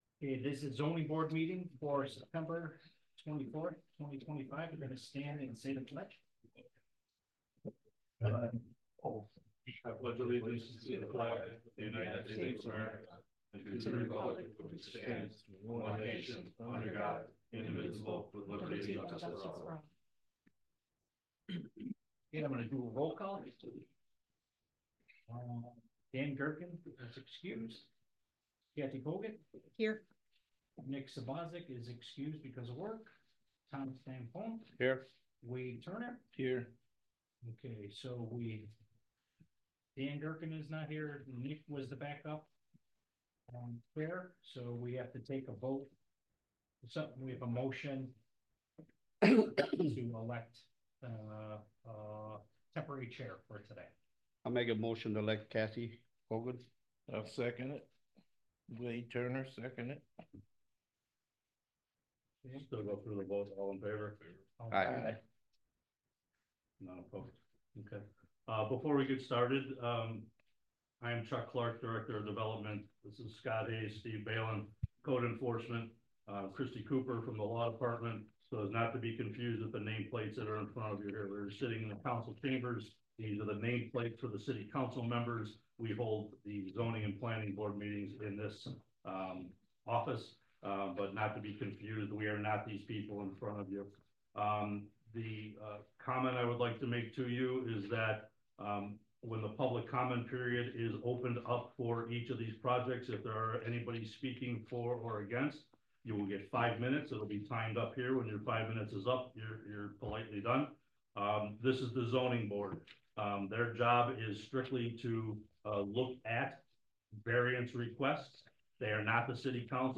Lackawanna-Zoining-Board-Meeting-9-25-25.mp3